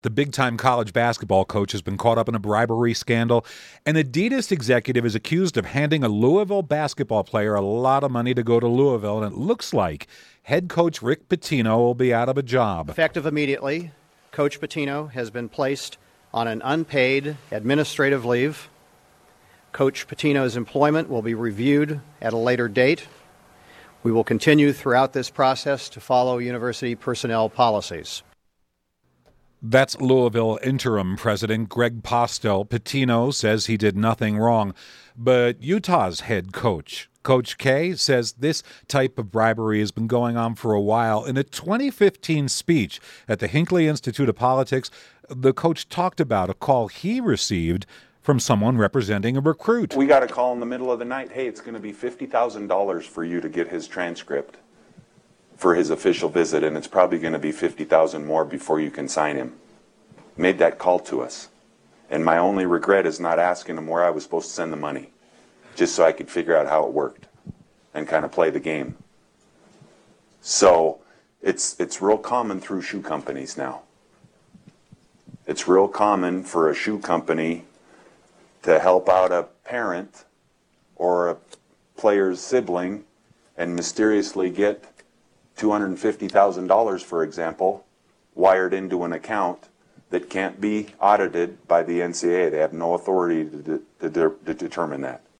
He talked to a group at the Hinckley Institute of Politics about the problems of shoe companies get involved in recruiting and shared one of his experiences.